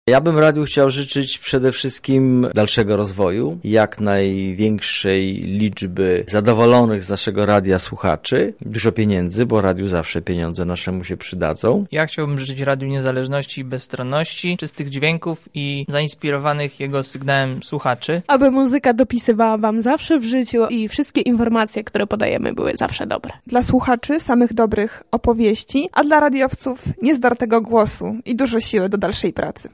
W tym wyjątkowym dniu, my radiowcy składamy jubilatowi życzenia:
SONDA